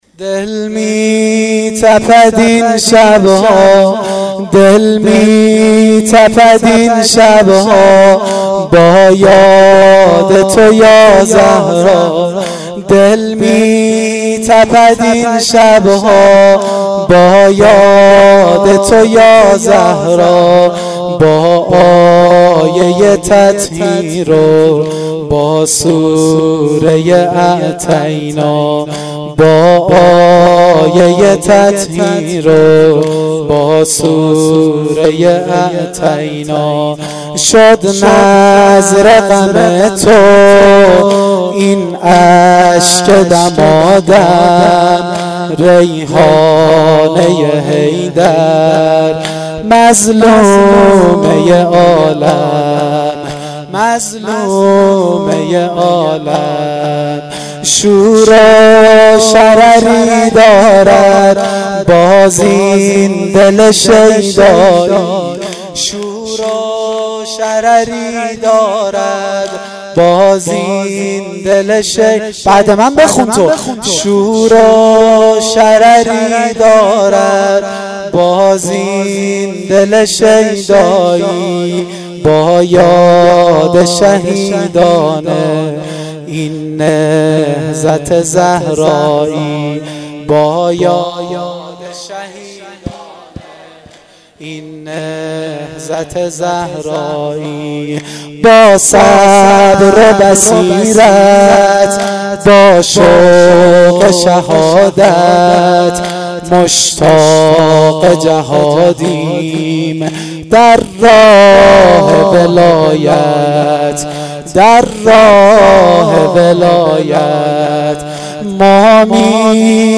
دم پایانی هیئت جواد الائمه شب اول فاطمیه